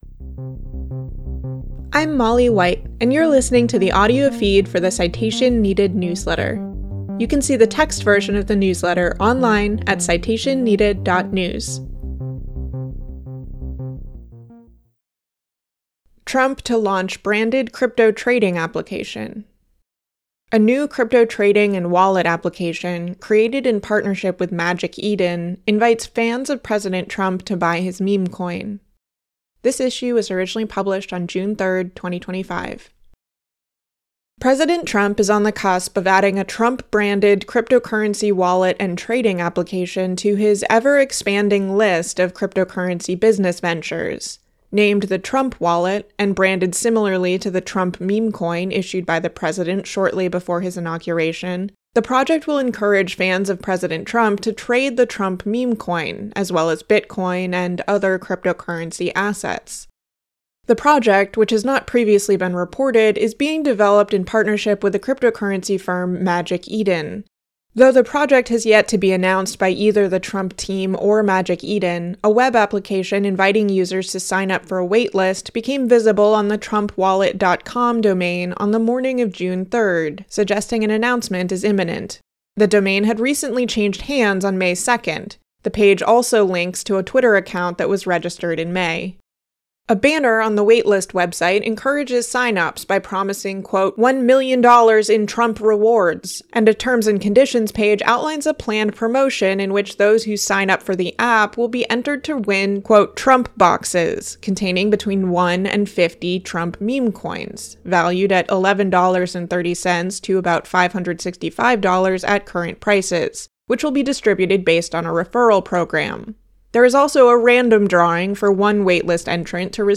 Listen to me read this post here (not an AI-generated voice!), subscribe to the feed in your podcast app, or download the recording for later.